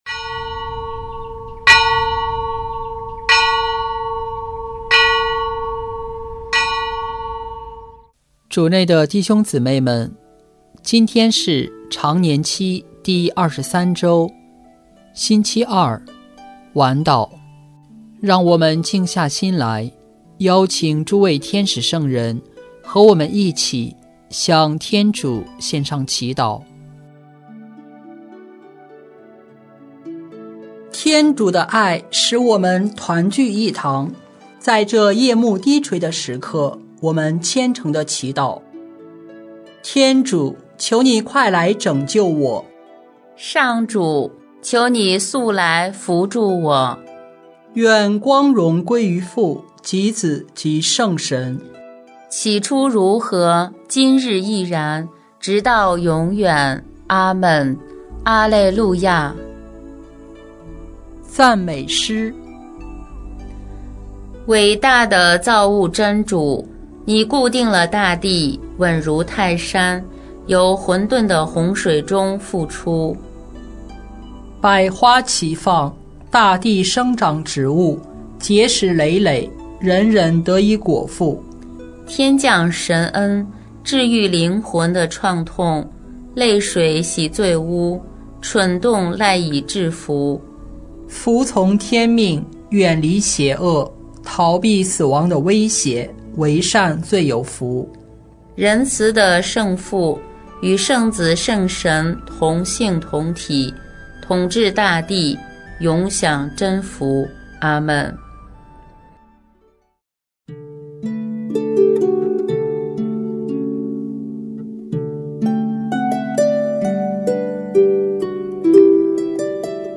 【每日礼赞】|9月9日常年期第二十三周星期二晚祷